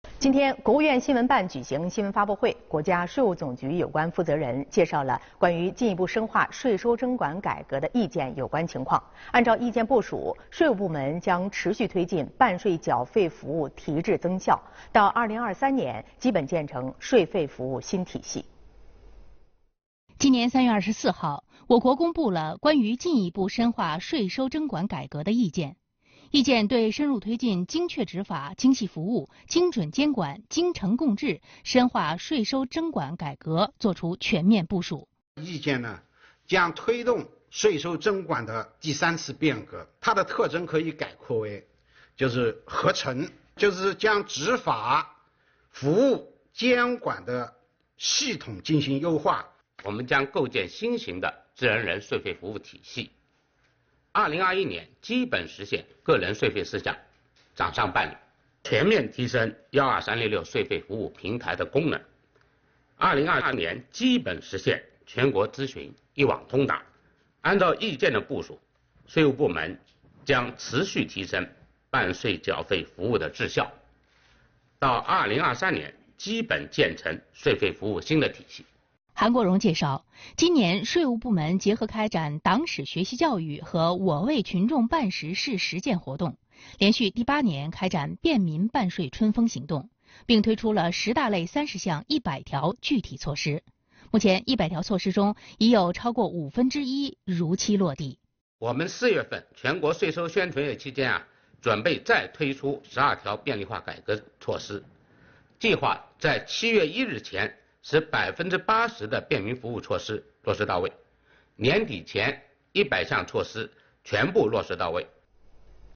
视频来源：央视《新闻直播间》
今天（3月29日）国务院新闻办举行新闻发布会，国家税务总局有关负责人介绍了《关于进一步深化税收征管改革的意见》有关情况。